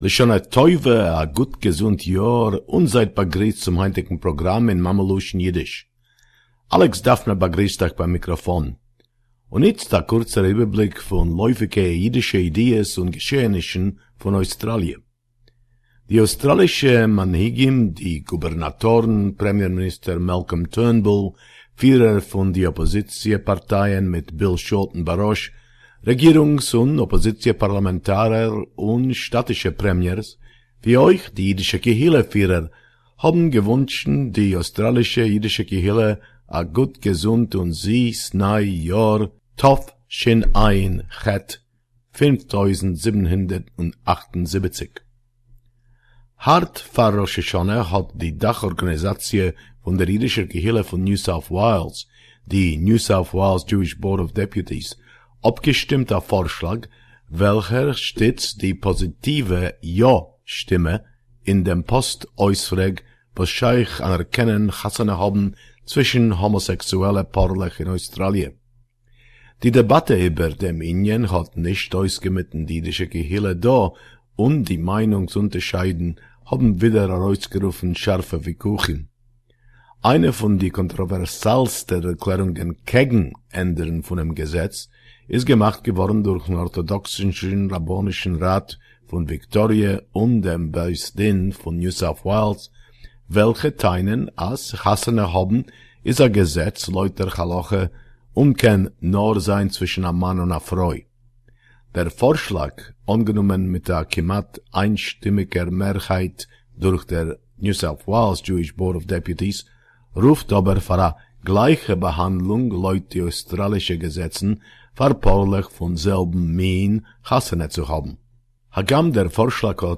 The only Radio report in Yiddish, in the world...Don't miss out!